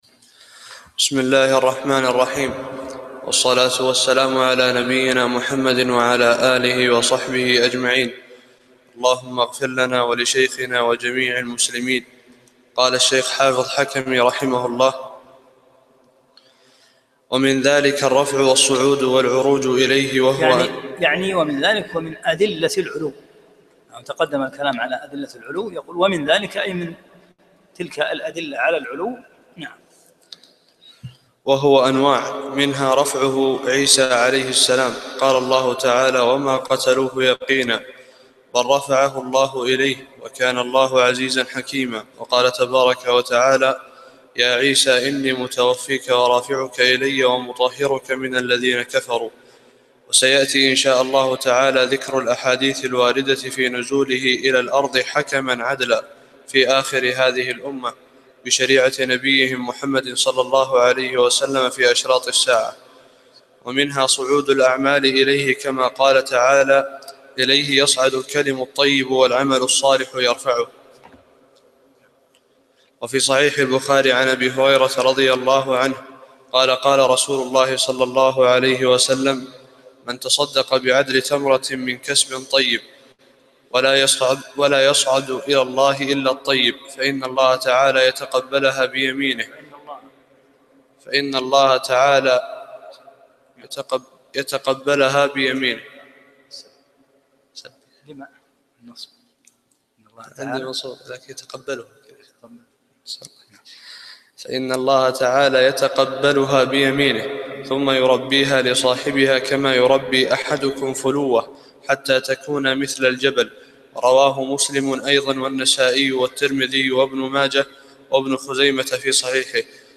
17- الدرس السابع عشر